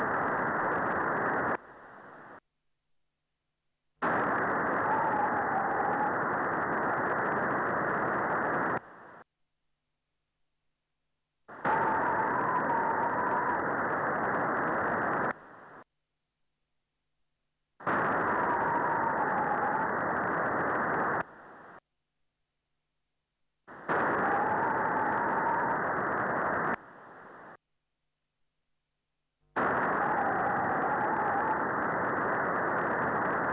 Echo CW WAV
echo_cw.wav